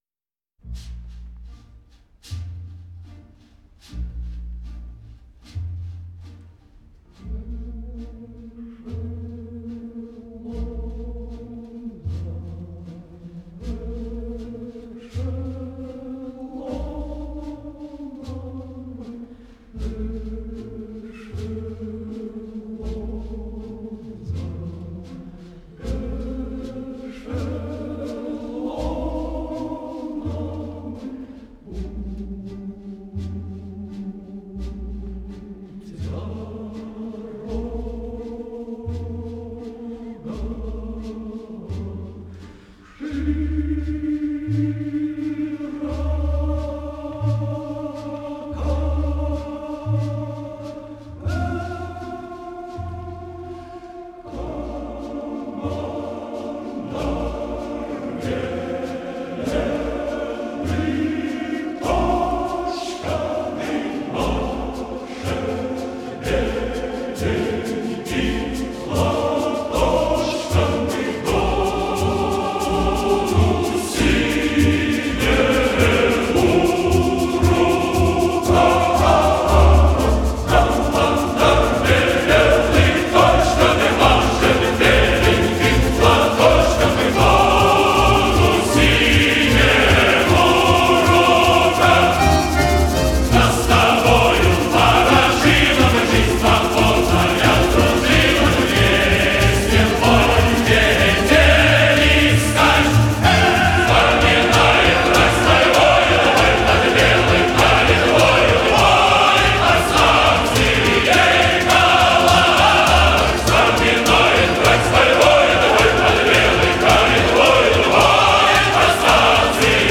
Поздняя запись